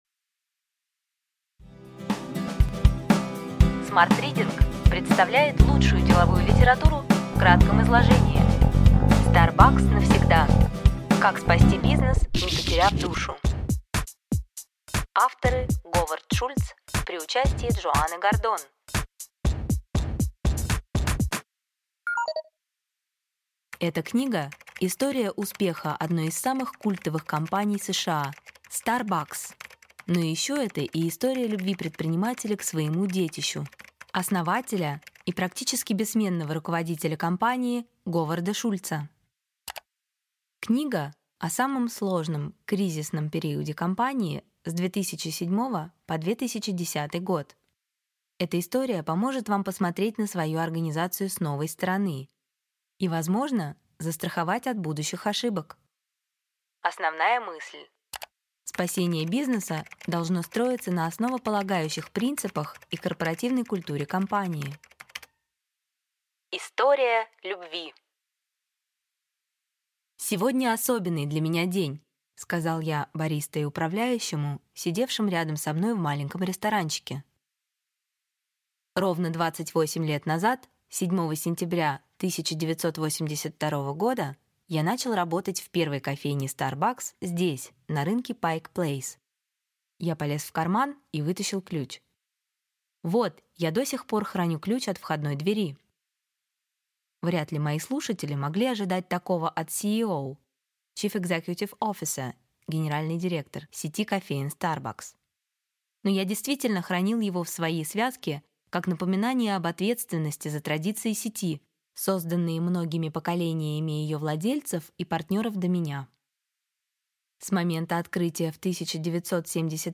Аудиокнига Ключевые идеи книги: Starbucks навсегда. Как спасти бизнес, не потеряв душу. Говард Шульц, при участии Джоанны Гордон | Библиотека аудиокниг